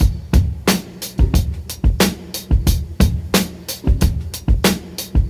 90 Bpm Drum Loop G Key.wav
Free drum groove - kick tuned to the G note. Loudest frequency: 1667Hz
90-bpm-drum-loop-g-key-26z.ogg